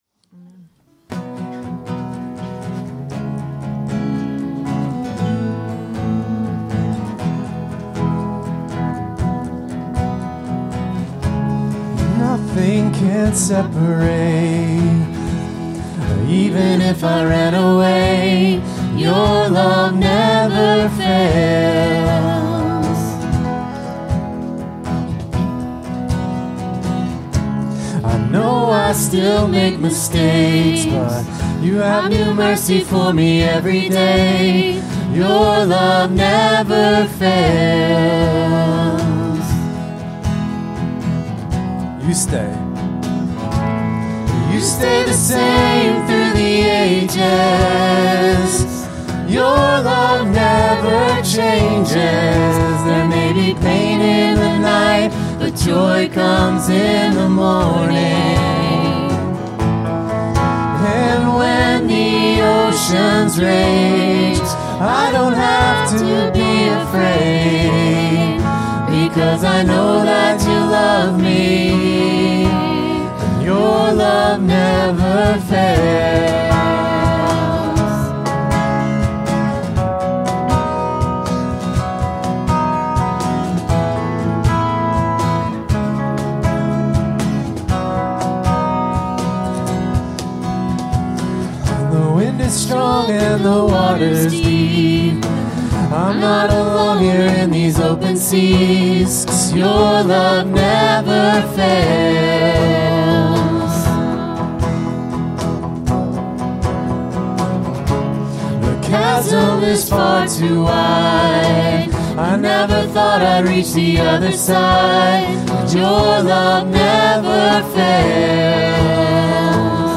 Worship 2024-10-13